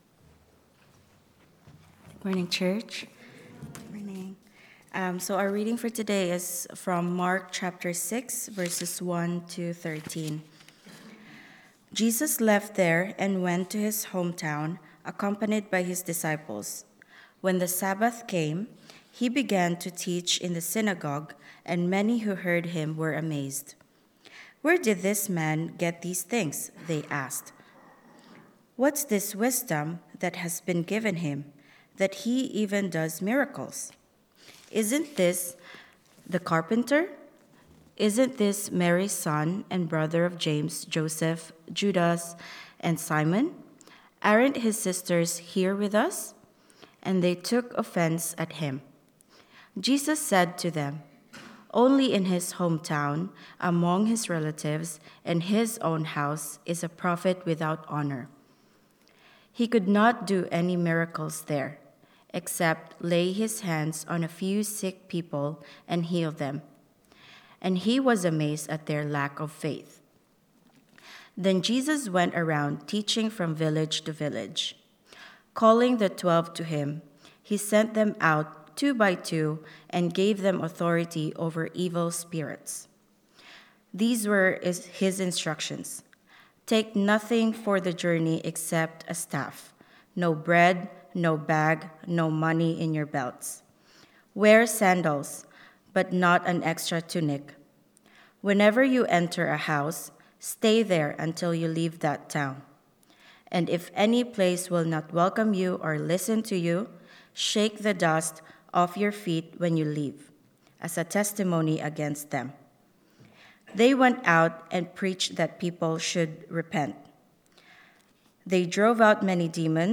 Reading
Reading-Mark-6_1-13.mp3